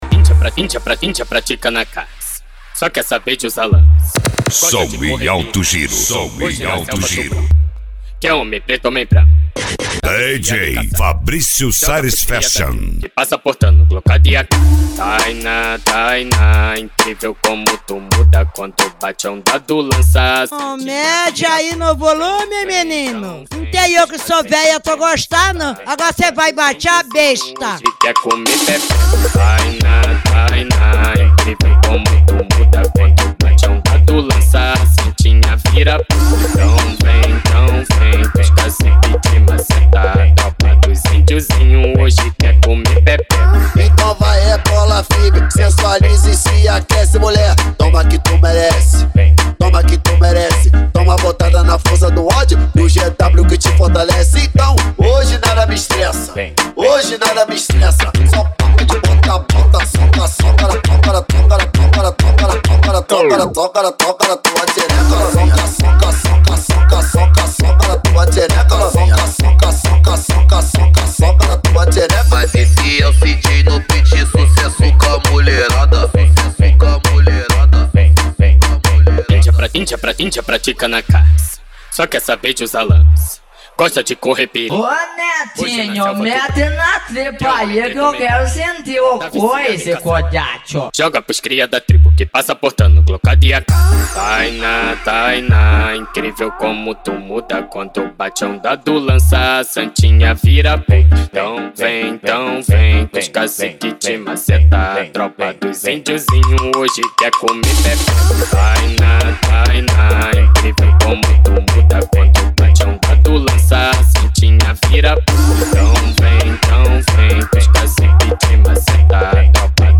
Bass
Funk
Mega Funk